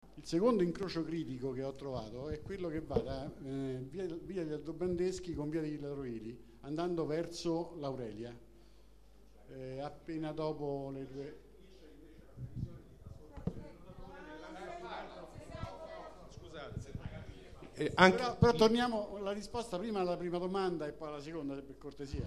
Assemblea
Registrazione integrale dell'incontro svoltosi l'8 aprile 2013 presso il Centro Anziani in via Baldassarre Longhena, 98